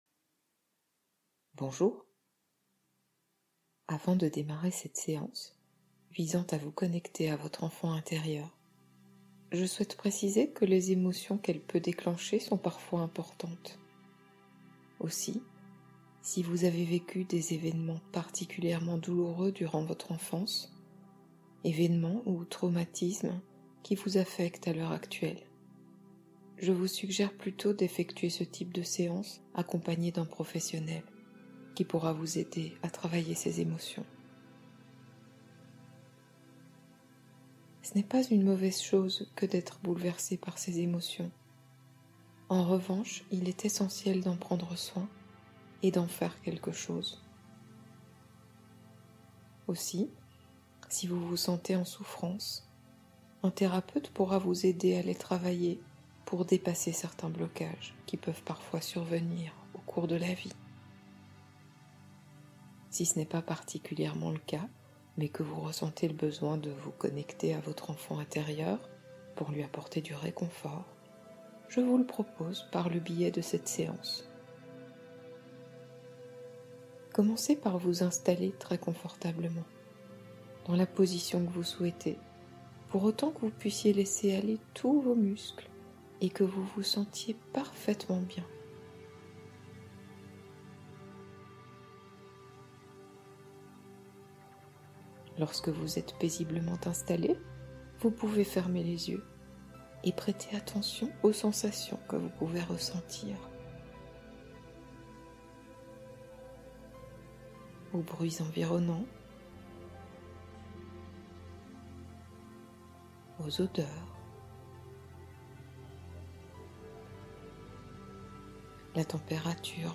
Relaxation Guidée Enfant intérieur : libération et apaisement